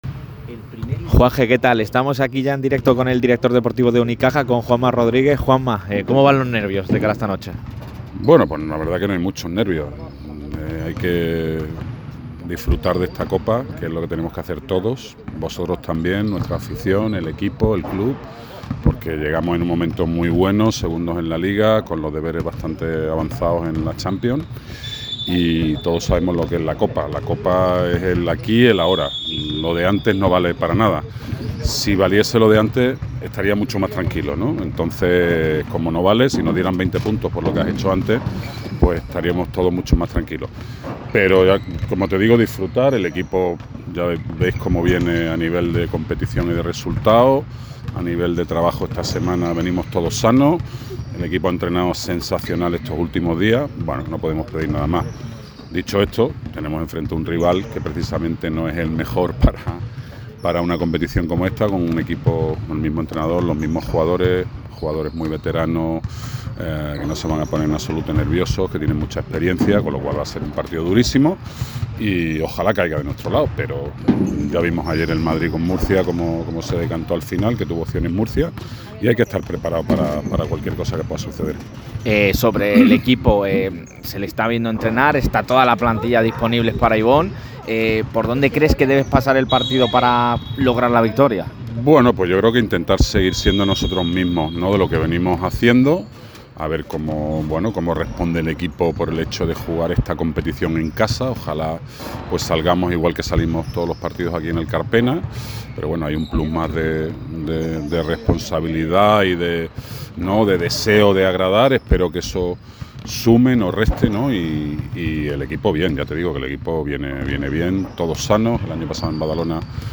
ha hablado sobre el partido de esta noche ante Lenovo Tenerife durante el último entrenamiento del equipo antes del duelo de los cuartos de final de la Copa ACB.